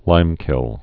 (līmkĭl, -kĭln)